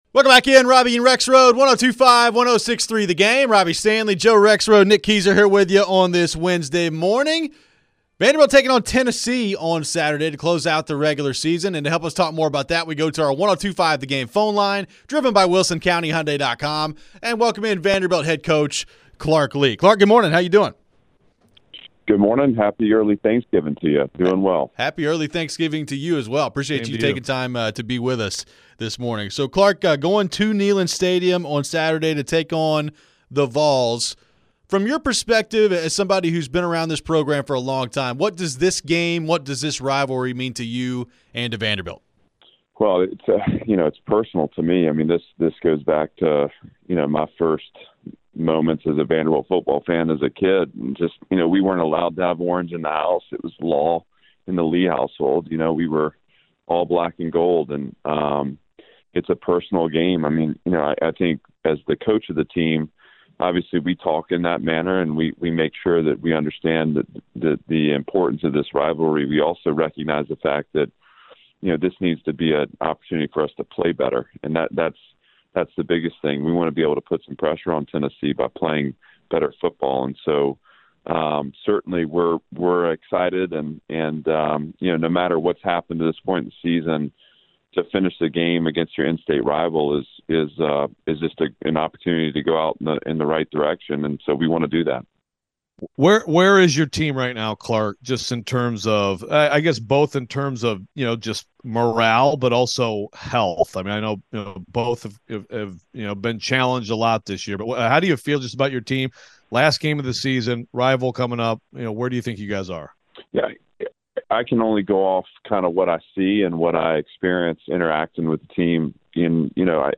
Clark Lea Interview (11-22-23)
Vanderbilt head football coach Clark Lea joined the show before they travel up to Neyland on Saturday to take on the Vols.